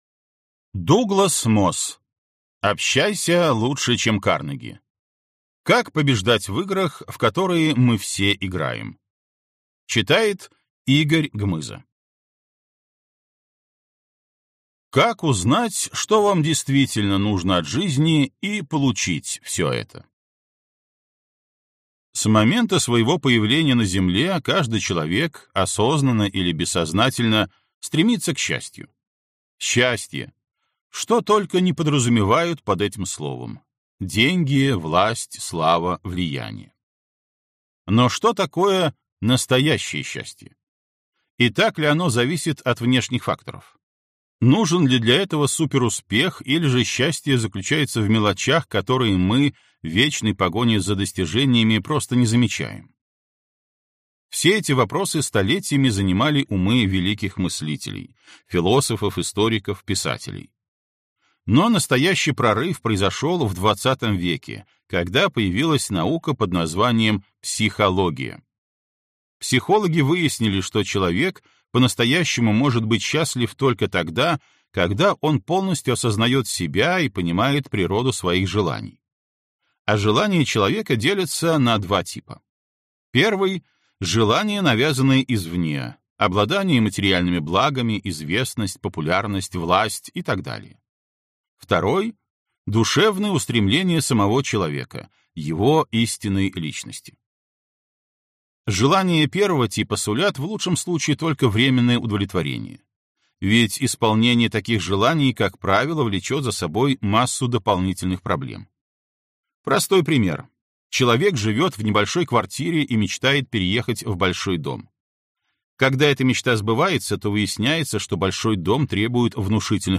Аудиокнига Общайся лучше, чем Карнеги. Как побеждать в играх, в которые мы все играем | Библиотека аудиокниг